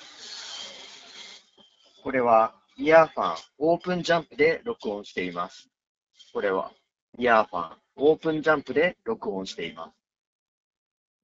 通話時のノイズキャンセリング
スピーカーから雑踏音をそこそこ大きなボリュームで流しながらマイクで収録した音声がこちら。
完全に雑音を除去しているわけではないですが、これくらいなら通話相手が聞き取りにくいことはなさそうです。
マイクも若干こもり気味なものの悪くはないので、仕事でのちょっとした打ち合わせ程度であれば十分使えると思います。
earfun-openjump-voice.m4a